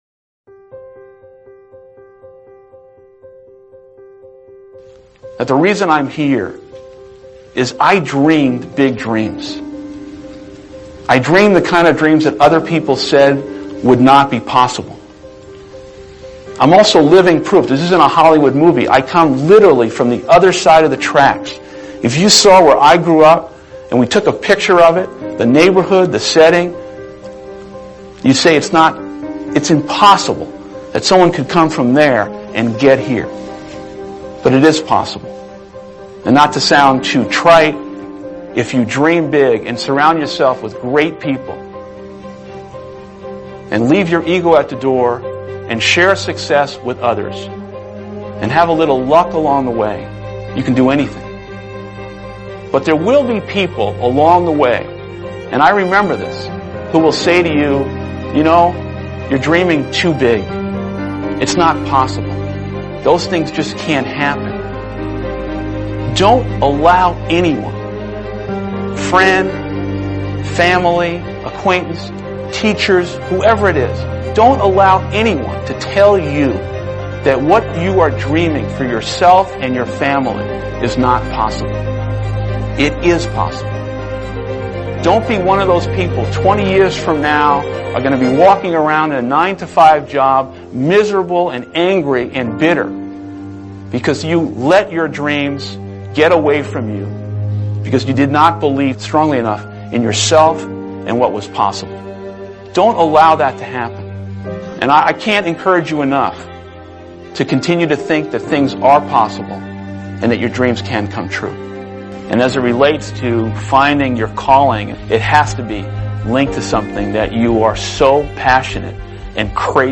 Speaker: Howard Schultz